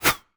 bullet_flyby_fast_10.wav